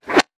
weapon_bullet_flyby_21.wav